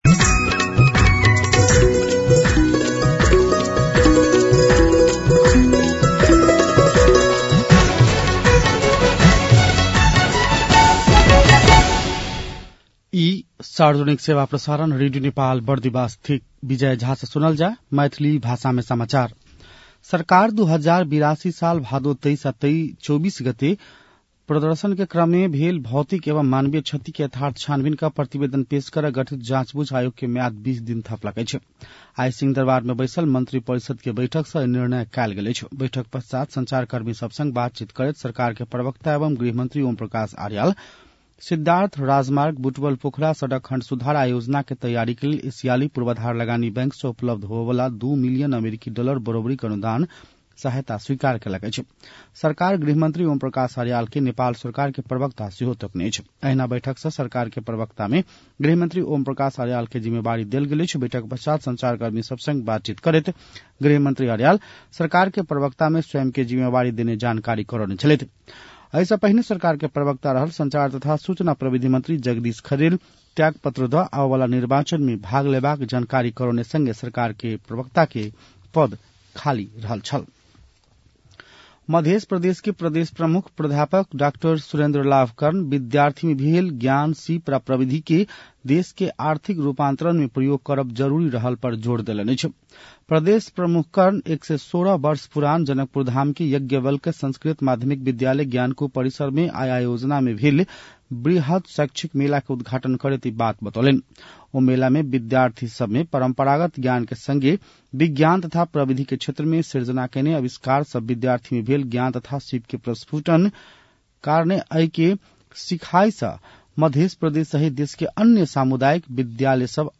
मैथिली भाषामा समाचार : ८ माघ , २०८२
6.-pm-maithali-news-1-7.mp3